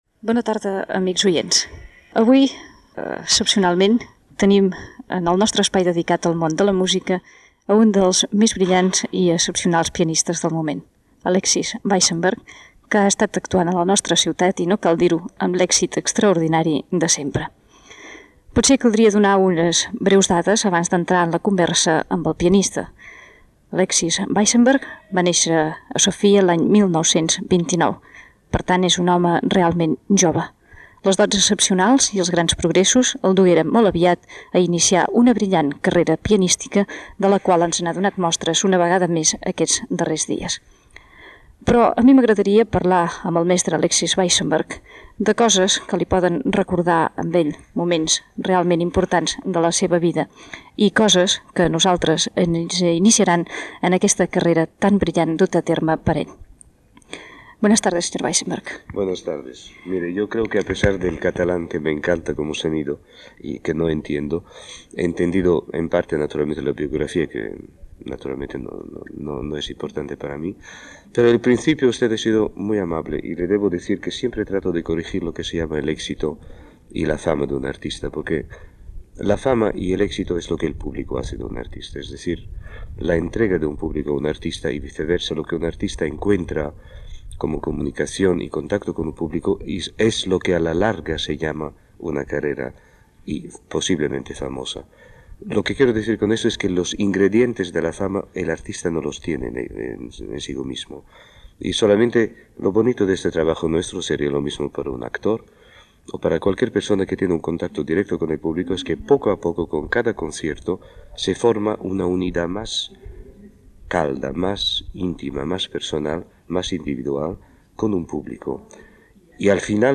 Perfil biogràfic del pianista Alesis Weissenberg i entrevista
Musical